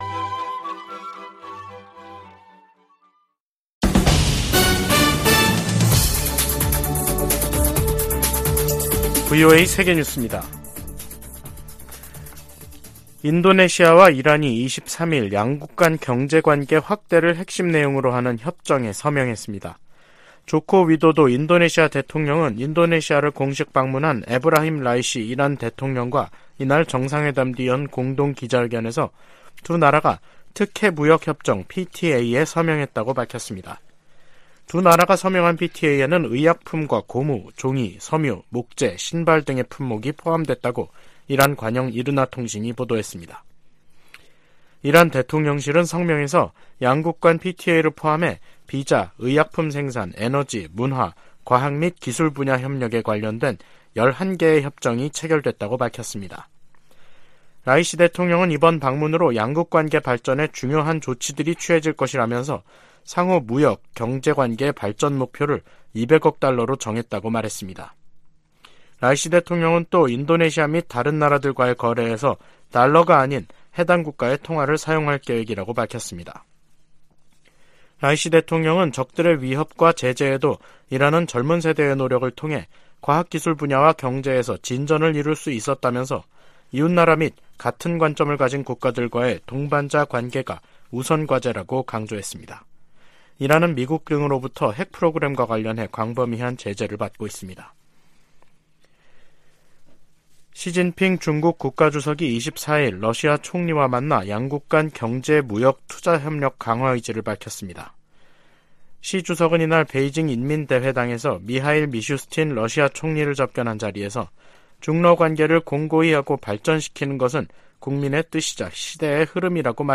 VOA 한국어 간판 뉴스 프로그램 '뉴스 투데이', 2023년 5월 24일 3부 방송입니다. 미 재무부가 북한의 불법 무기 프로그램 개발에 필요한 자금 조달과 사이버 활동에 관여한 기관 4곳과 개인 1명을 제재했습니다. 북한이 27년 연속 미국의 테러 방지 노력에 협조하지 않는 나라로 지정됐습니다. 북한 핵역량 고도화로 한국에서 자체 핵무장론까지 나온 상황은 워싱턴에 경종을 울린다고 전직 백악관 고위 관리들이 말했습니다.